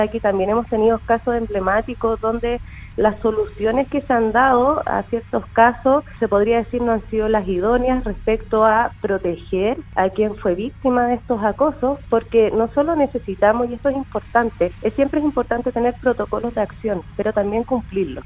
En conversación con Radio Sago, la Seremi de la Mujer y Equidad de Género, Macarena Gré, sostuvo que se debe analizar el cómo resguardar los espacios en donde se generan los hechos de violencia, los cuales ocurren a las afueras de los establecimientos educacionales.